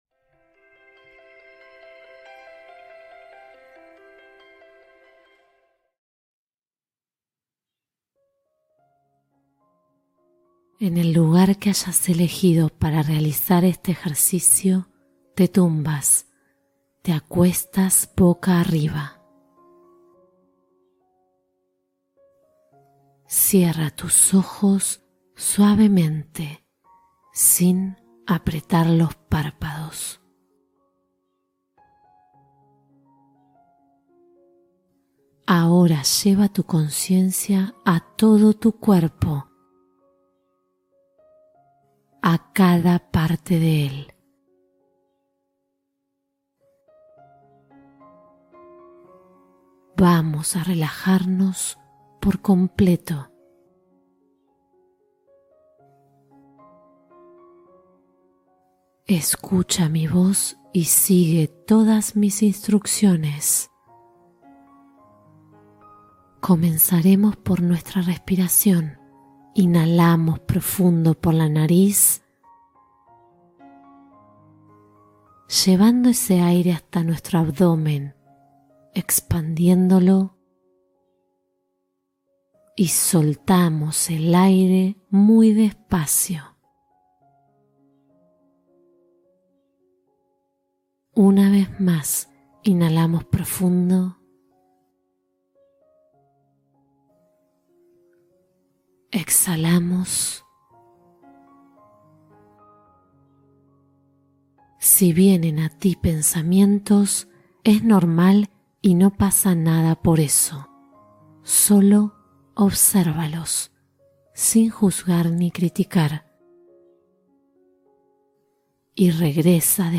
Duerme profundamente con esta meditación guiada de 10 minutos